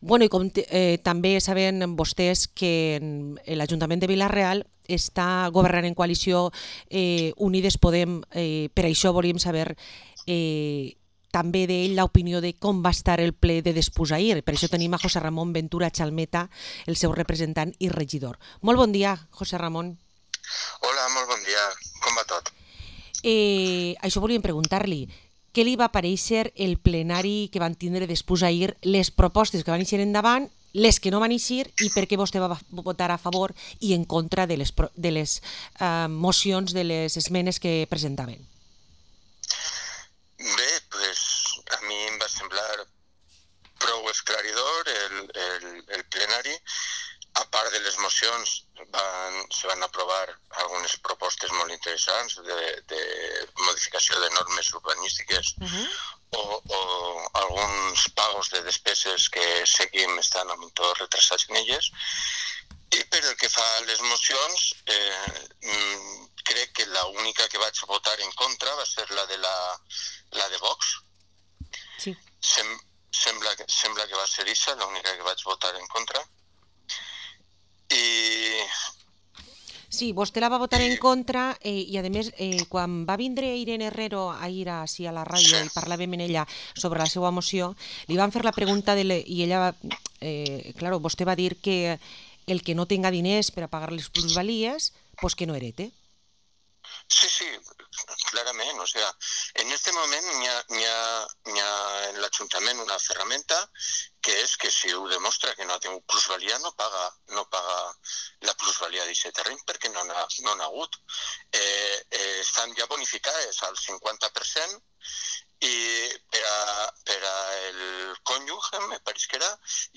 Entrevista a José Ramón Ventura, concejal de Unidas Podemos en el Ayuntamiento de Vila-real